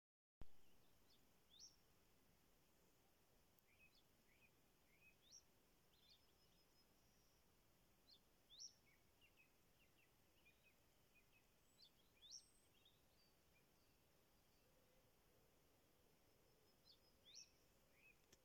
Putns (nenoteikts), Aves sp.
ПримечанияVarbūt kāds var pateikt, kas ir šis zvirbuļveidīgais čiepstētājs upes krastā, niedrēs.